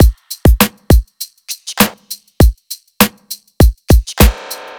FK100BEAT2-L.wav